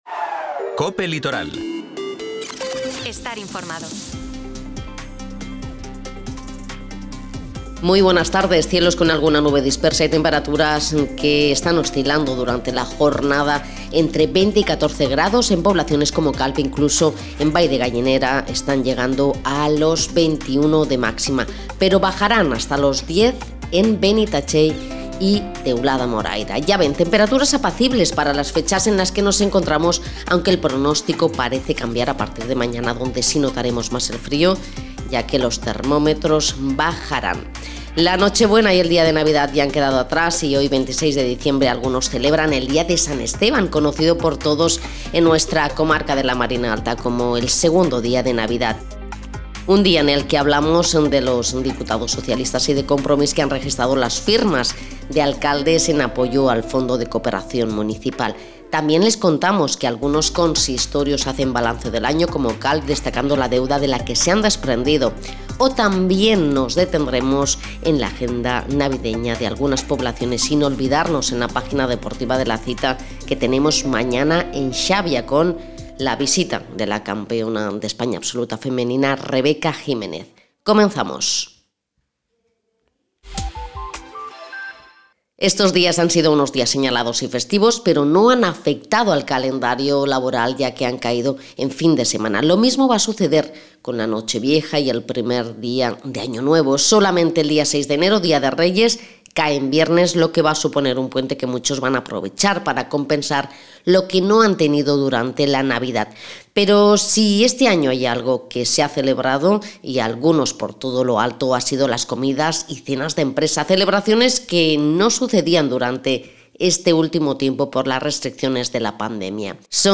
Informativo 26 diciembre de 2022